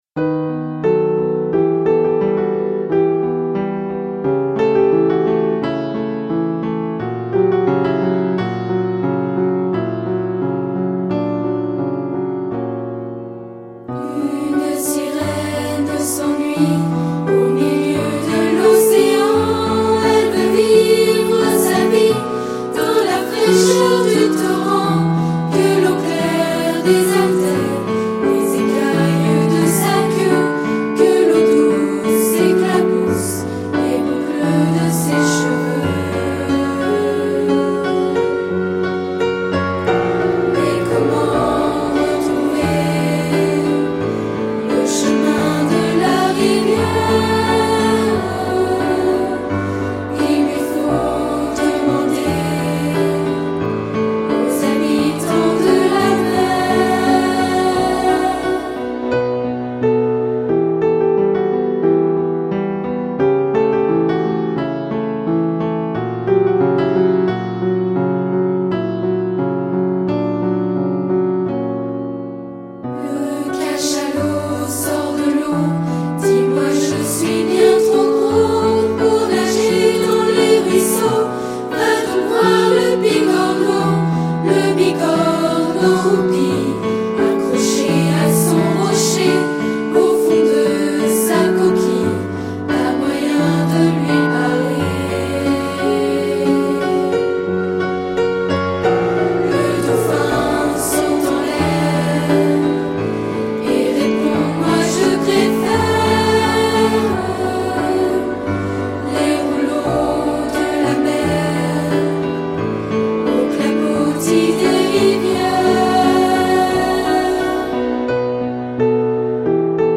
Version chantée :